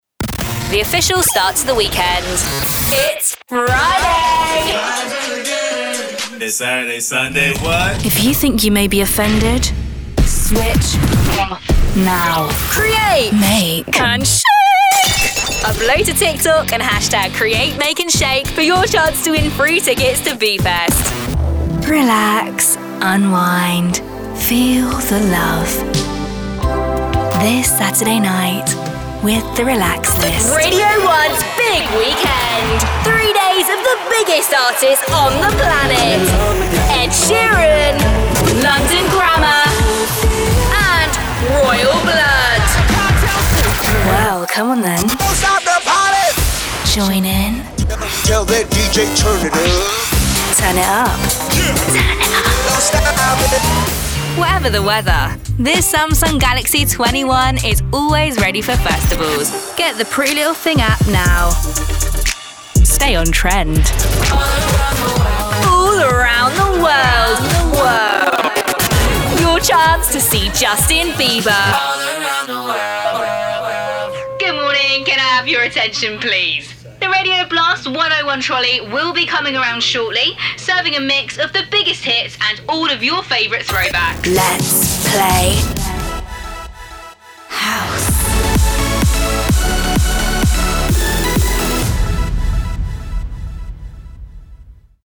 • Female
• Standard English R P
Showing: Promos & Idents Clips
Uplifting, Confident